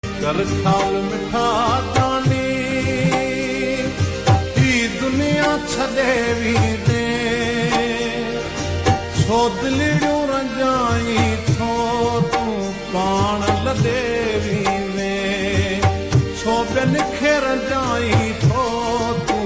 Sindhi song